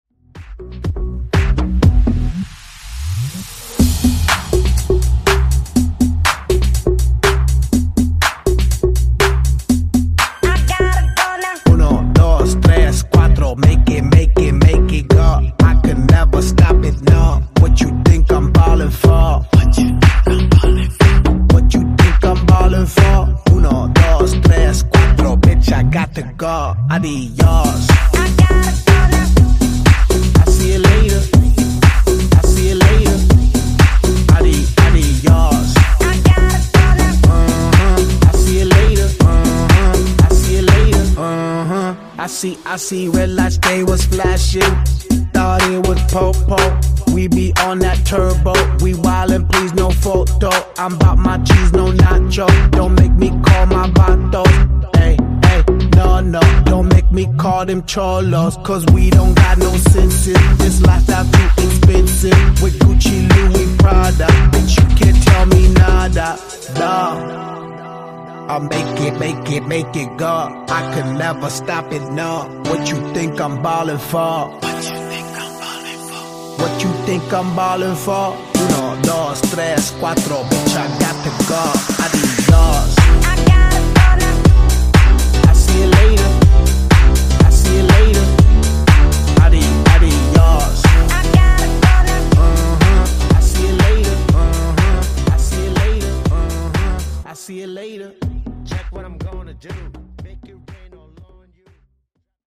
Genre: RE-DRUM
Clean BPM: 126 Time